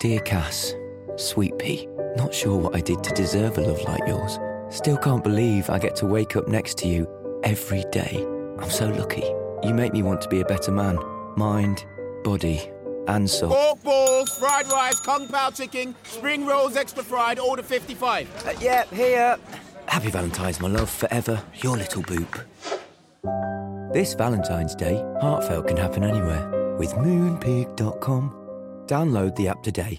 30/40's Light Midlands/US Comedic/Gentle/Expressive
Commercial Showreel